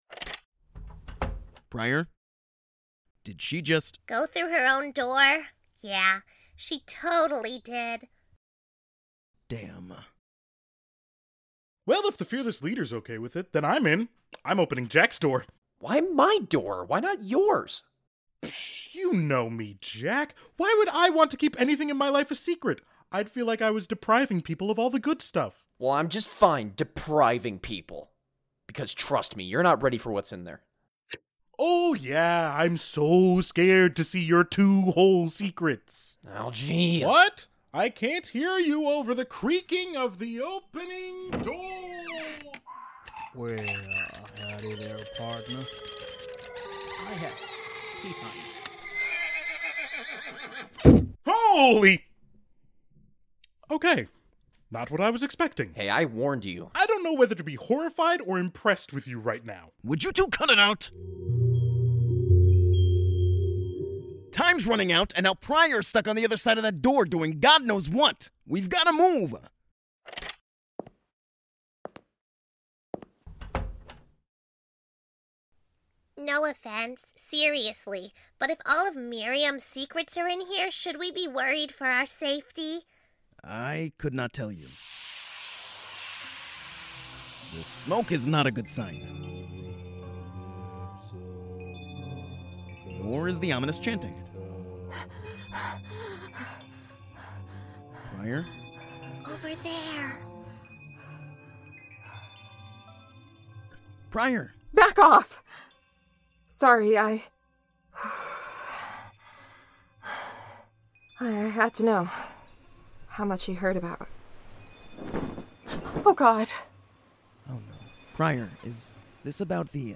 Comedy Audio Drama